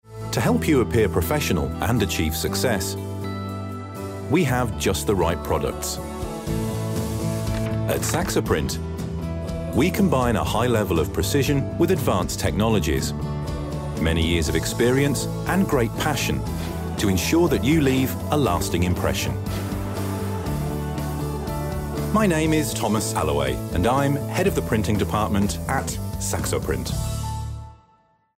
English (British)
Corporate Videos
Mic: SHURE SM7B